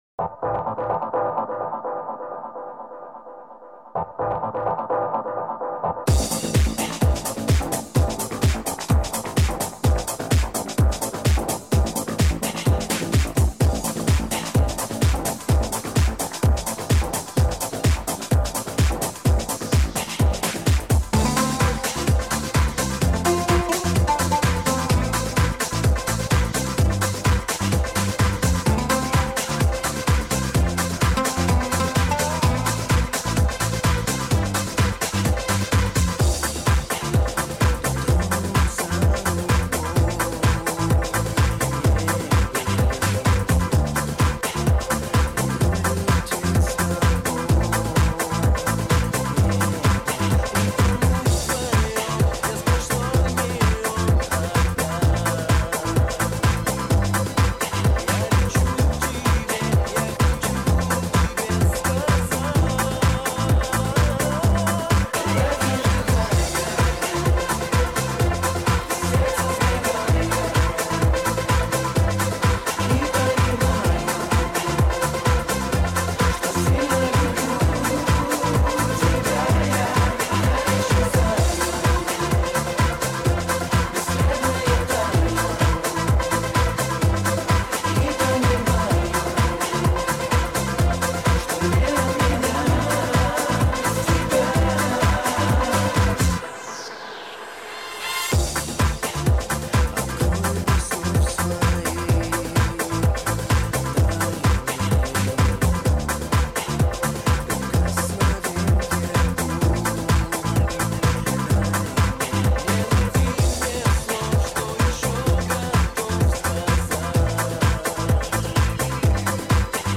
минусовка версия 22135